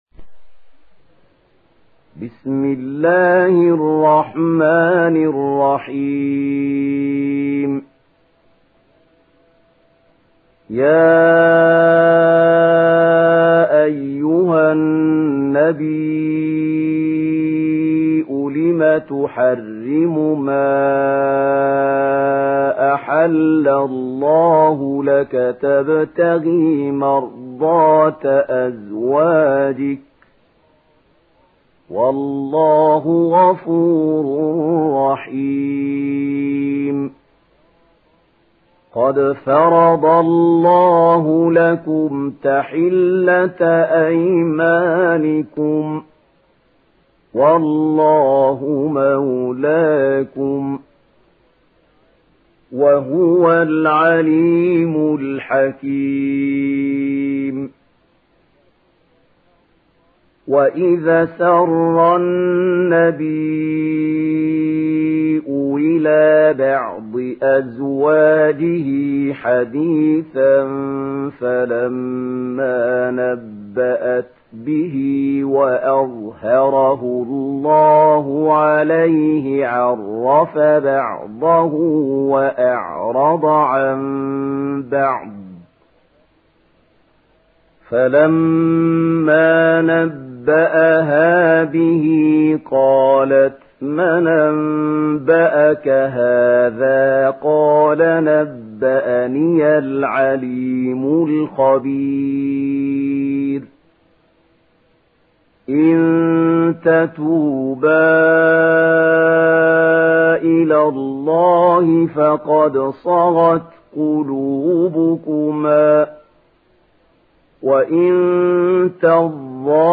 Surah At Tahrim mp3 Download Mahmoud Khalil Al Hussary (Riwayat Warsh)